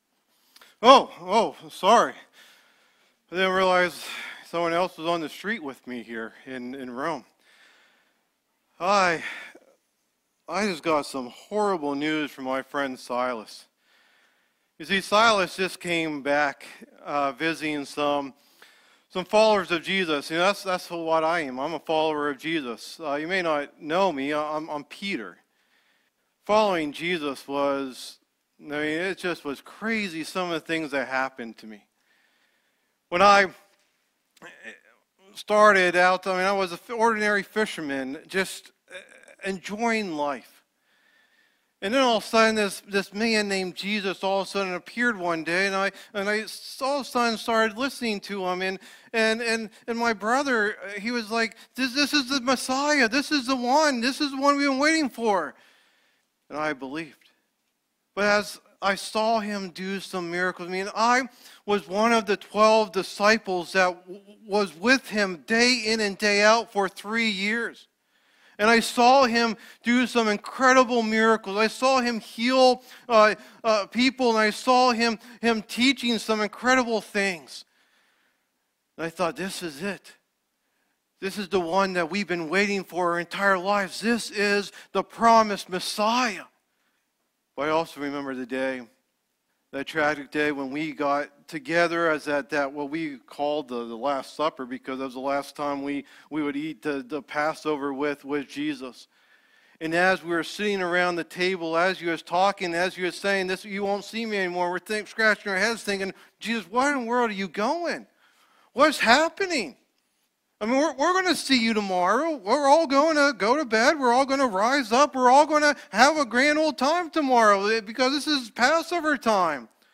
1st Person sermon